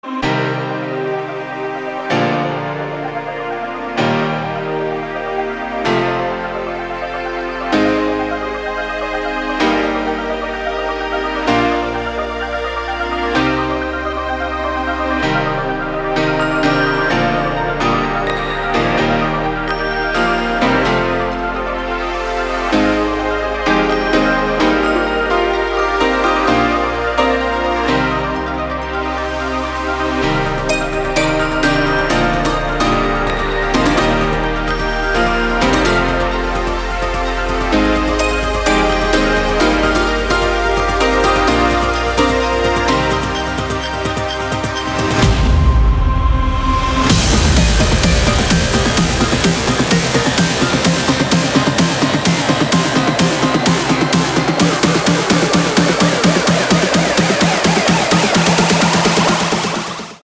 • Качество: 320, Stereo
красивые
спокойные
без слов
пианино
колокольчики